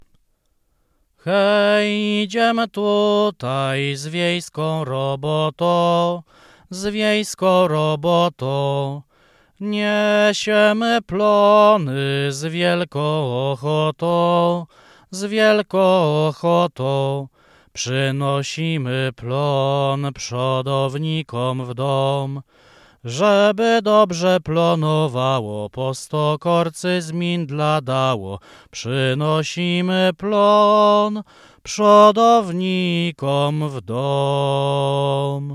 Kielecczyzna
Dozynkowa
dożynki dożynkowe lato okrężne żniwne